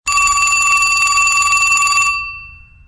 Antique_Phone.mp3